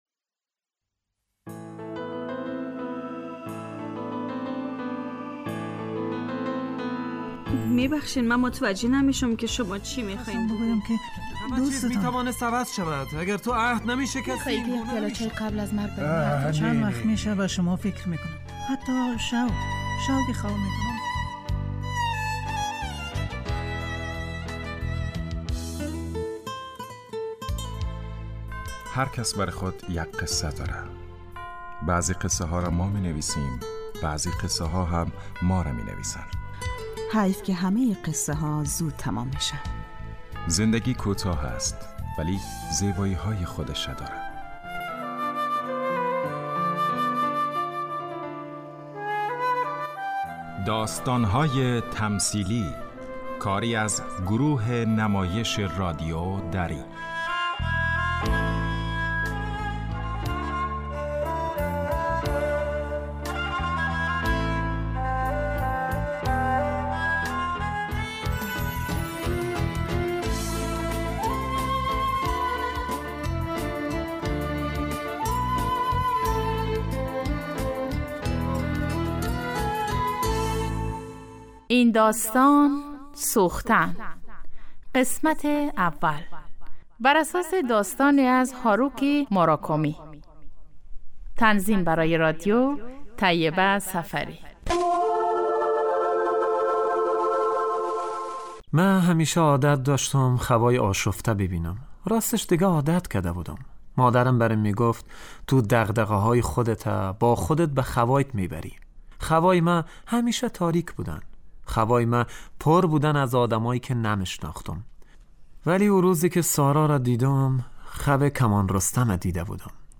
داستانهای تمثیلی نمایش 15 دقیقه ای هستند که روزهای دوشنبه تا پنج شنبه ساعت 03:25عصربه وقت وافغانستان پخش می شود.